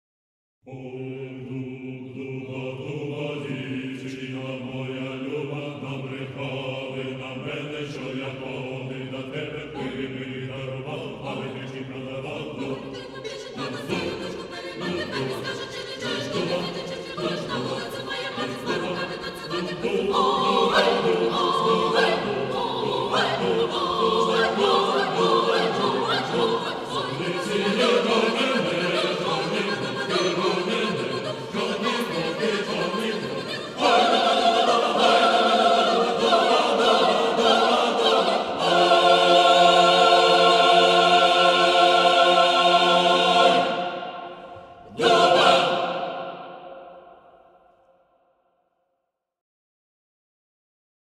chansons russes et ukrainiennes